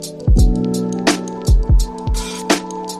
Free SFX sound effect: Sad Trombone.
Sad Trombone
555_sad_trombone.mp3